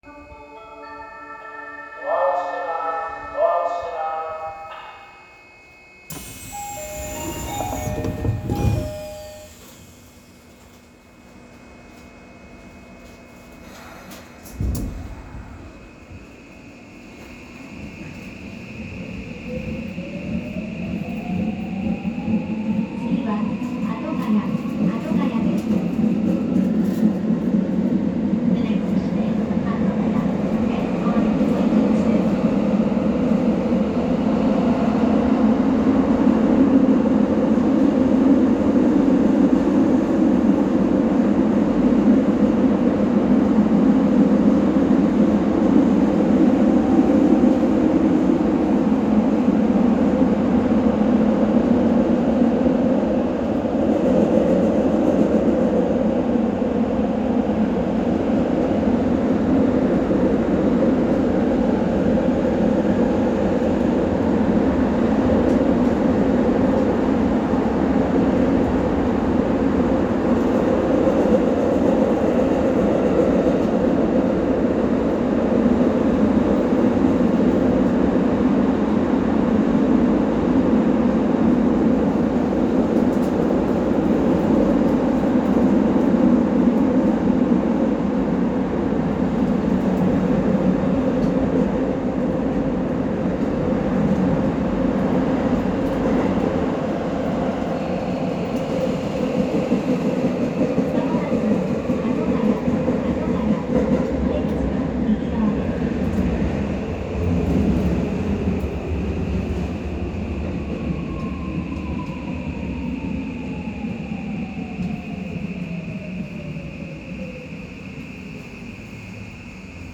・9000系更新車走行音
【埼玉高速線】戸塚安行→新井宿
更新車は三菱SiCとなり、ドアチャイムもJRタイプに変更されています。